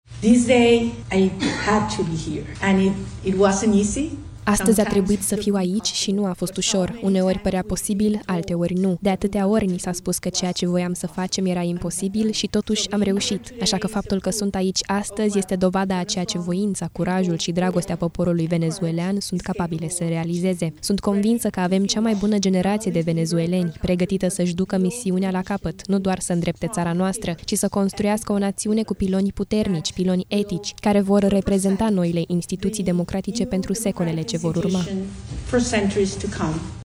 Maria Corina Machado a susținut la Oslo și o conferință de presă, unde a spus că se va întoarce în Venezuela, deși riscă arestarea.
11dec-15-Machado-discurs-TRADUS.mp3